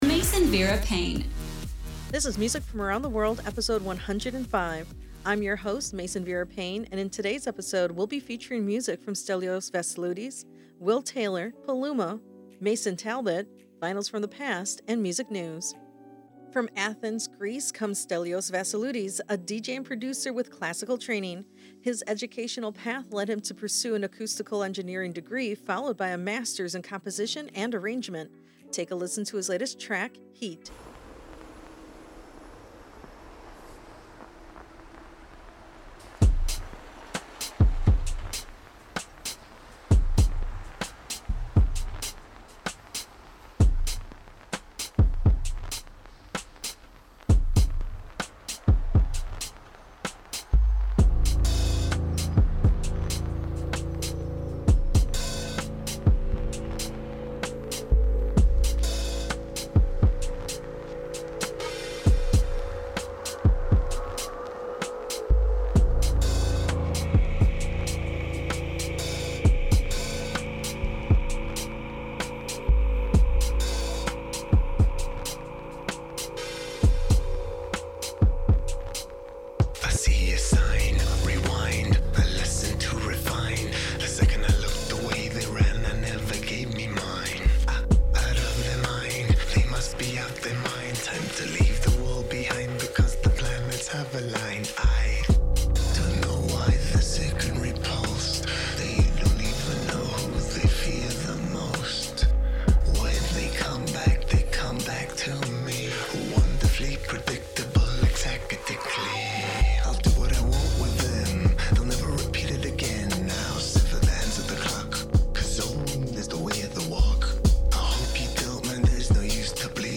a DJ and Producer with classical training.
UK Tech House